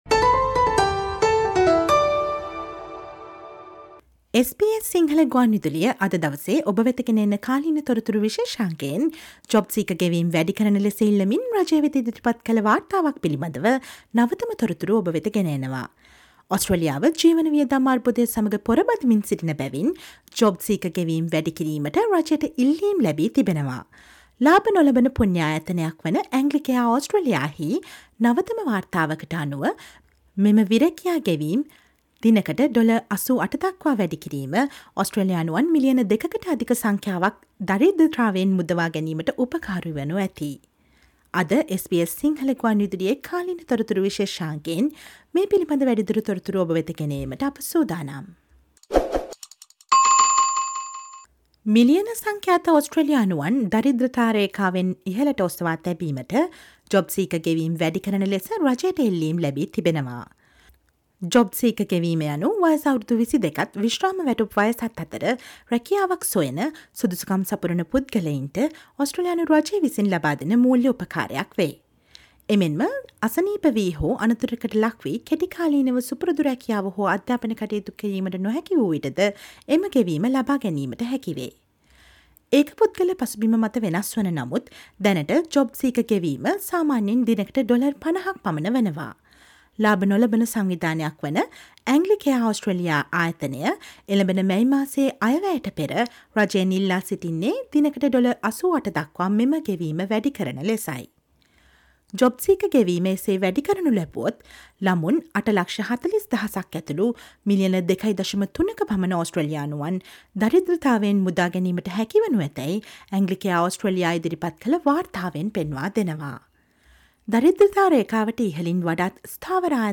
Listen to the SBS Sinhala radio current affair feature on calls for lifting jobseeker payment to lift millions of Australian out of poverty